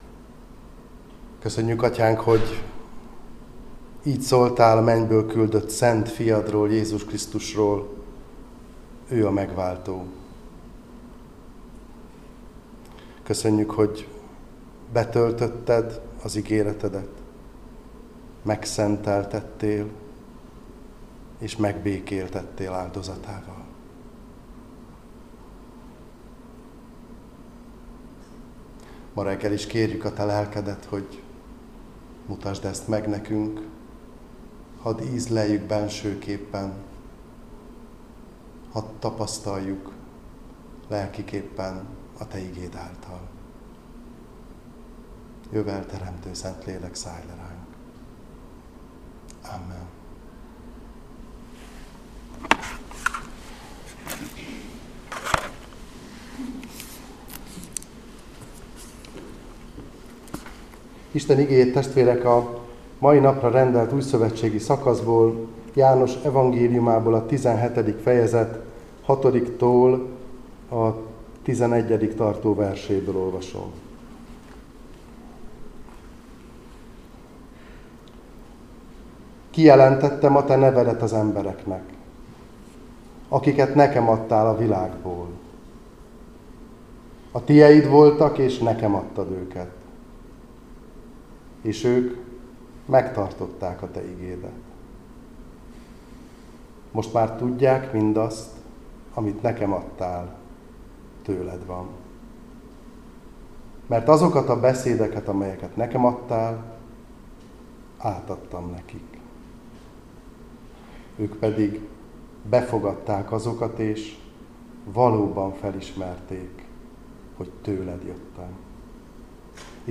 Áhítat, 2026. március 24.
egyetemi lelkész